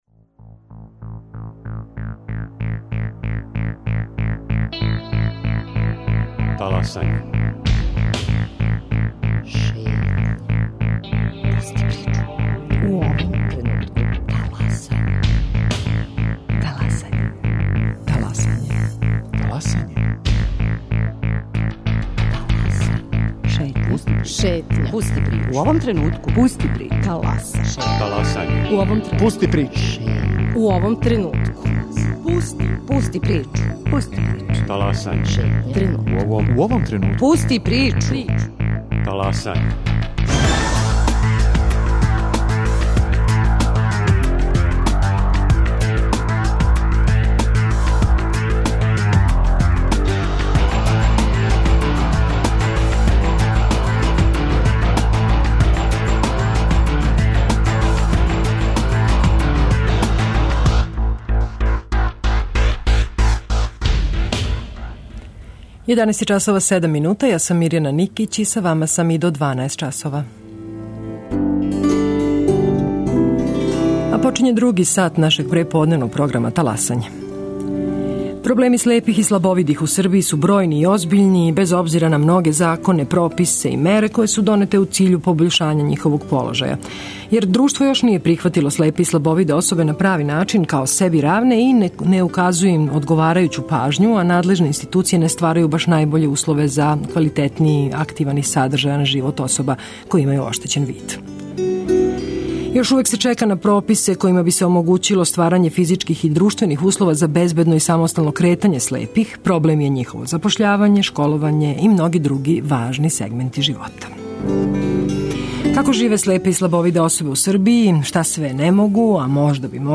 О свему томе, за Таласање говоре представници три удружења слепих и слабовидих особа.